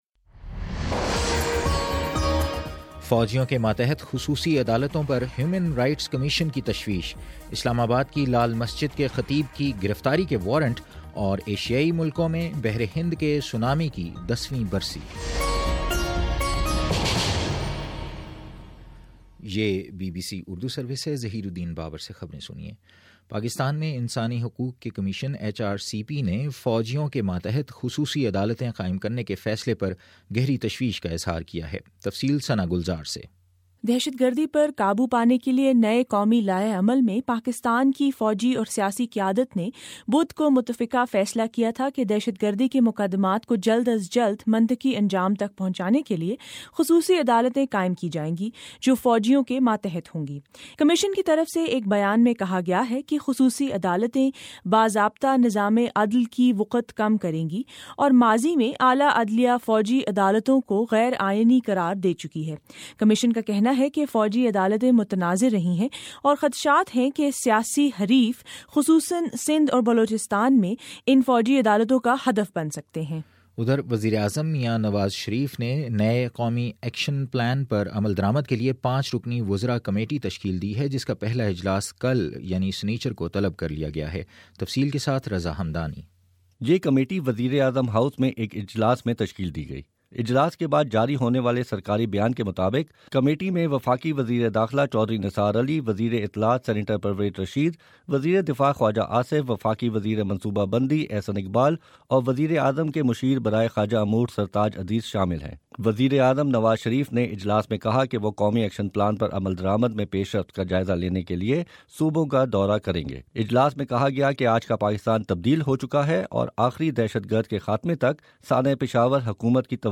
دسمبر 26 : شام چھ بجے کا نیوز بُلیٹن